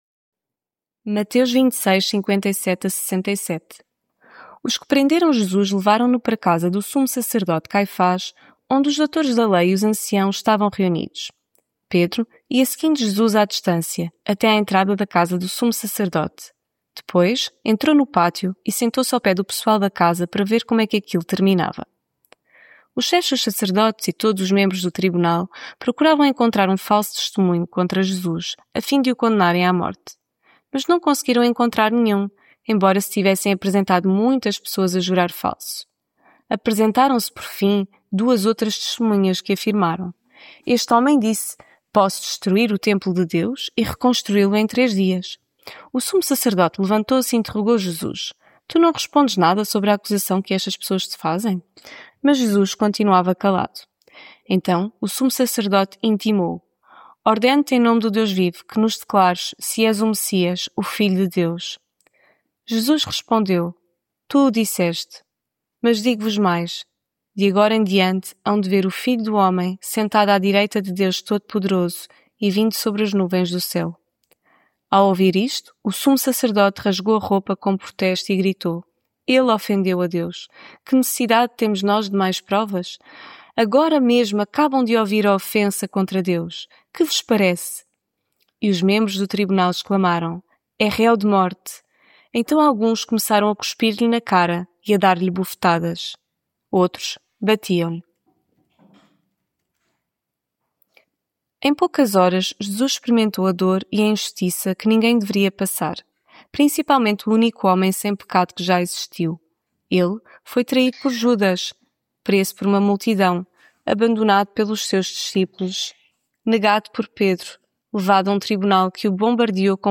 Devocional Quaresma Os que prenderam Jesus levaram-no para casa do sumo sacerdote Caifás, onde os doutores da lei e os anciãos estavam reunidos.